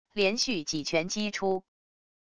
连续几拳击出wav音频